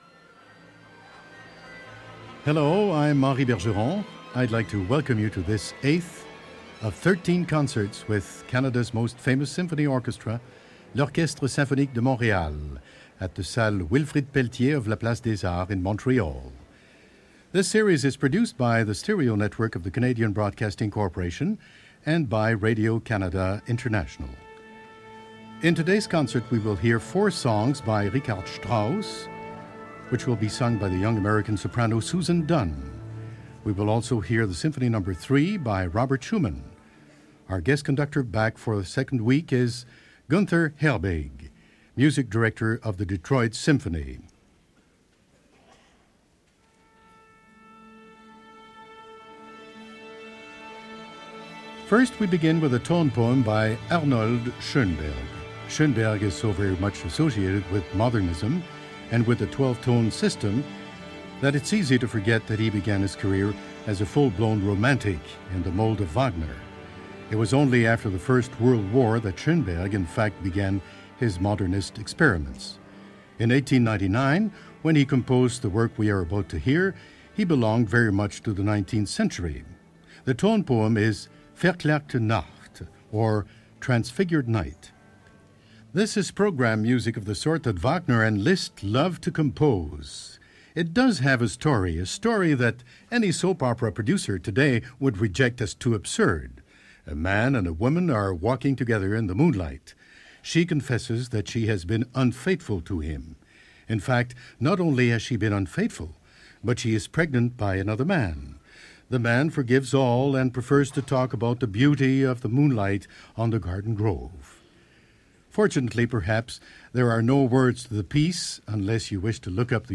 – Orchestre Philharmonique de Montreal – Gunther Herbig, guest Cond. Susan Dunn, Sop.
Heading up to Canada this week for a historic concert featuring Orchestre Philharmonique de Montreal, guest conducted by Gunther Herbig and featuring Soprano Susan Dunn in the Four Last Songs of Richard Strauss.